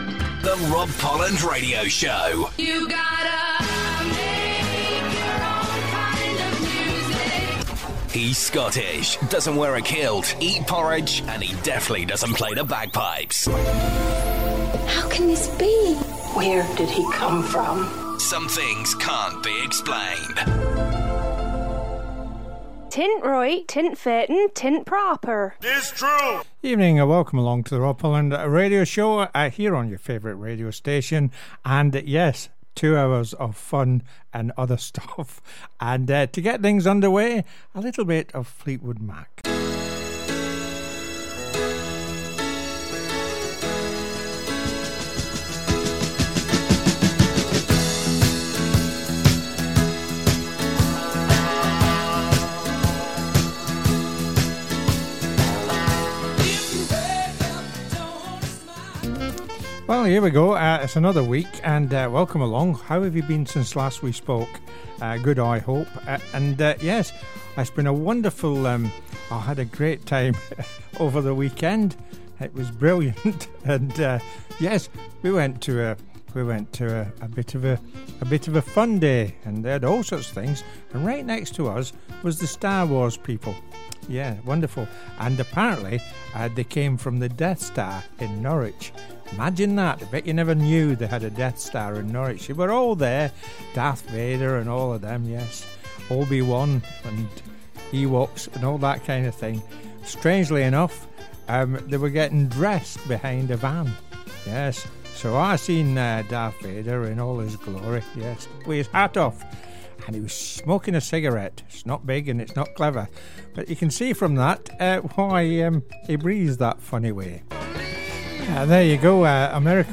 It’s good old-fashioned radio entertainment!